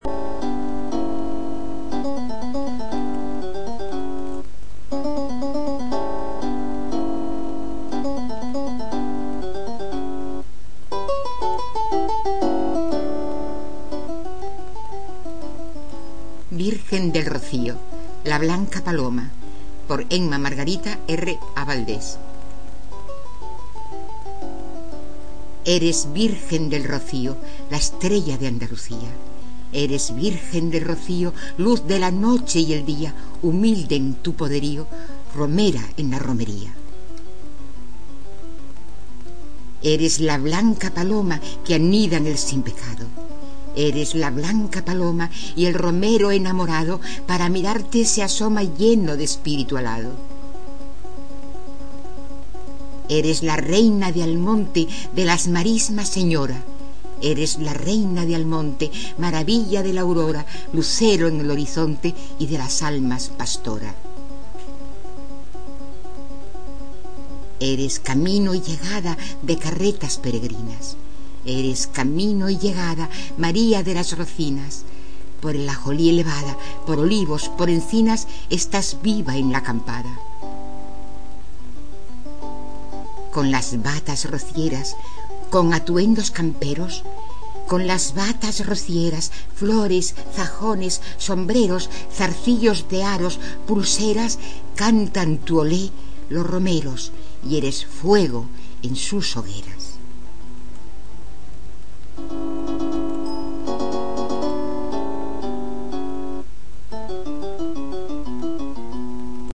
recitadas por la autora.